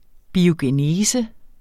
Udtale [ biogeˈneːsə ]